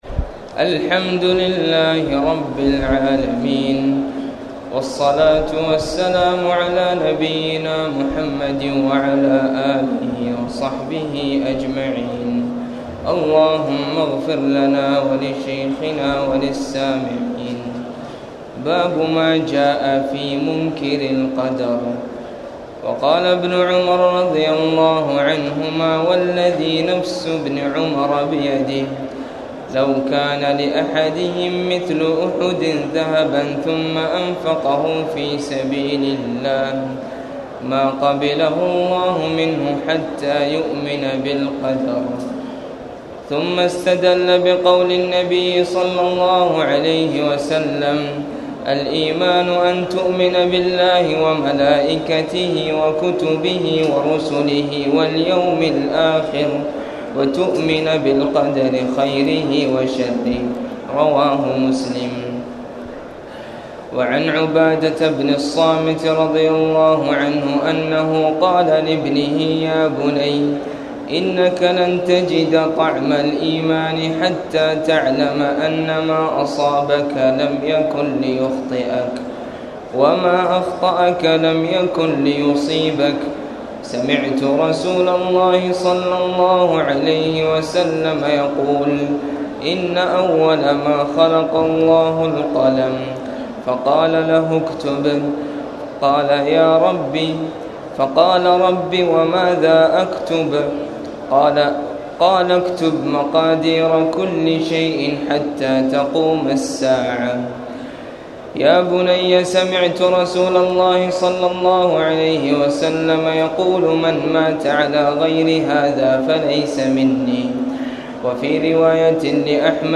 تاريخ النشر ٢٢ رمضان ١٤٣٨ هـ المكان: المسجد الحرام الشيخ